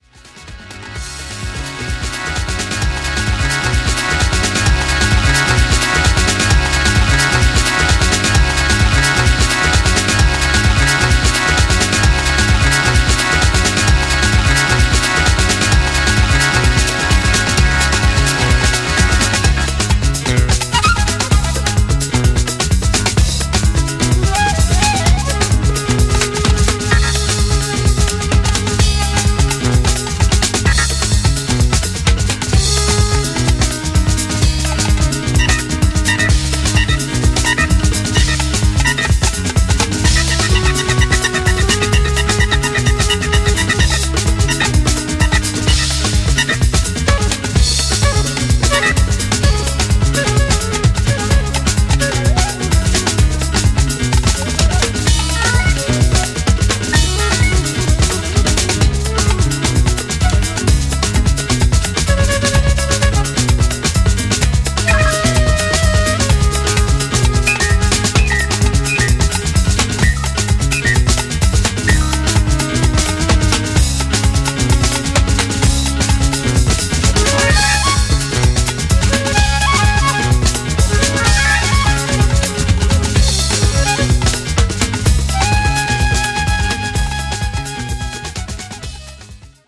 Three killer disco classics re-worked by a legend.